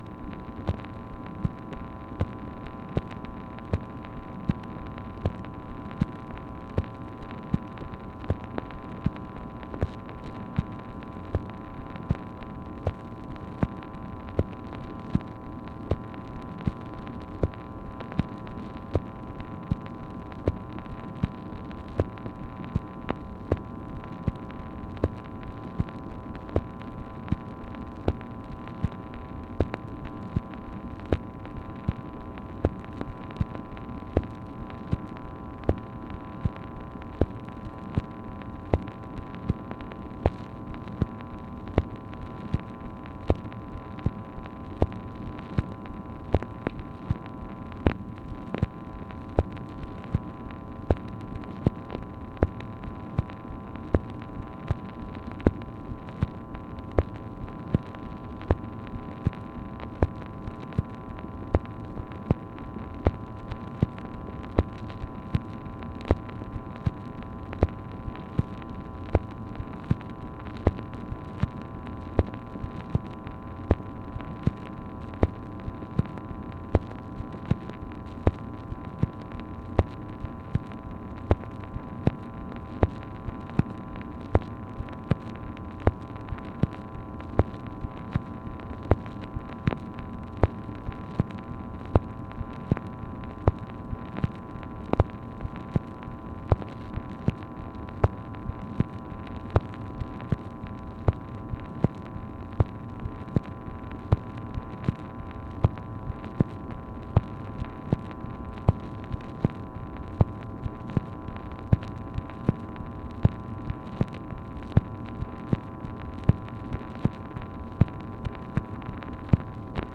MACHINE NOISE, November 13, 1965
Secret White House Tapes | Lyndon B. Johnson Presidency